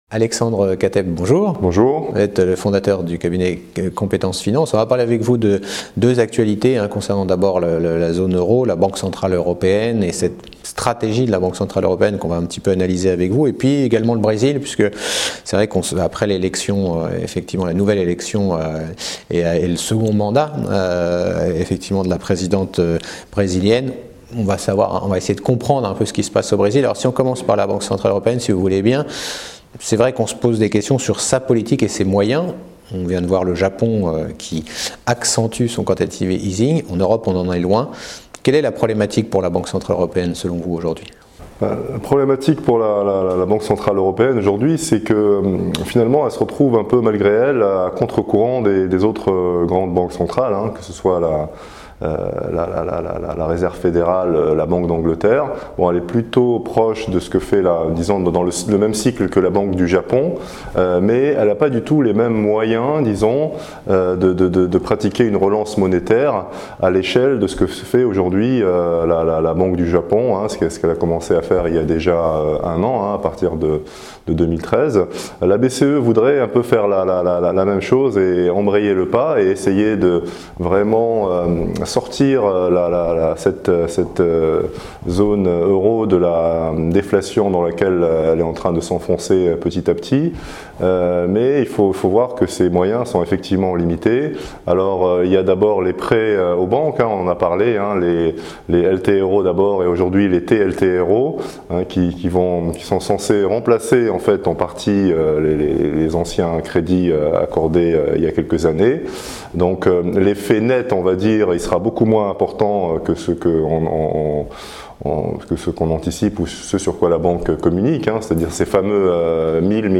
Cet interview a été tournée au Club Confair , 54 rue Laffite, 75009 Paris